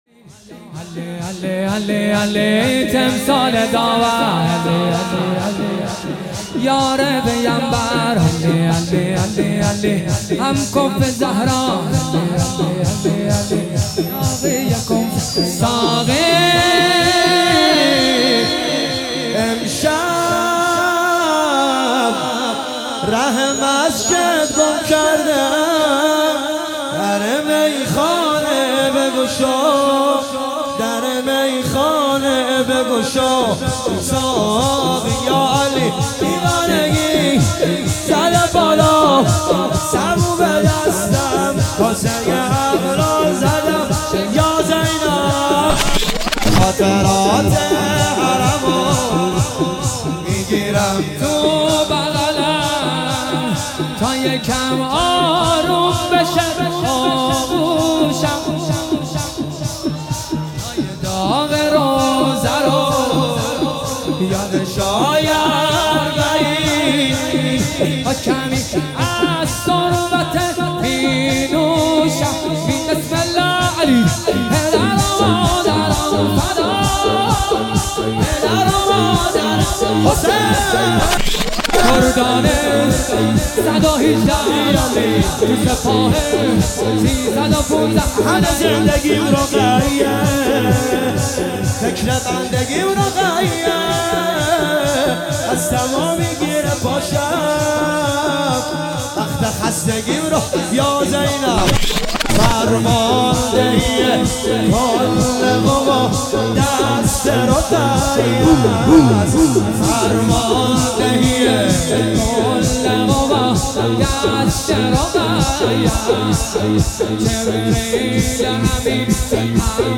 شور ترکیبی